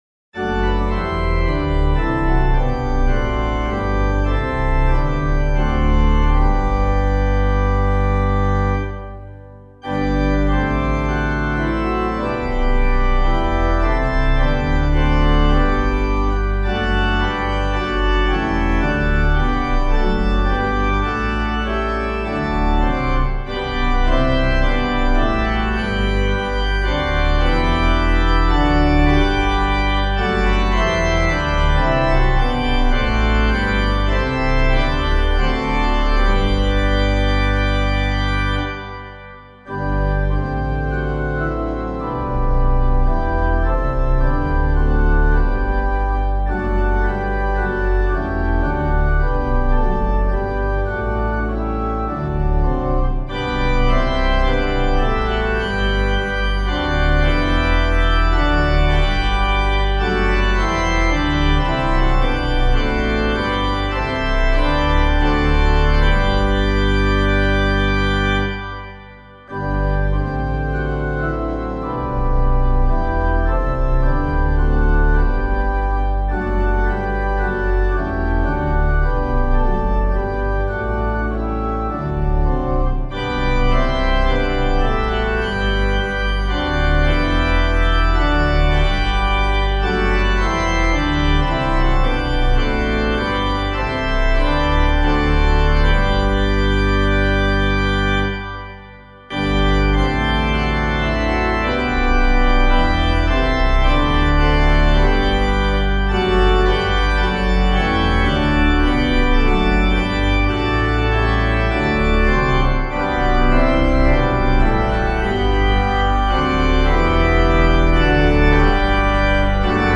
Hymn suitable for Catholic liturgy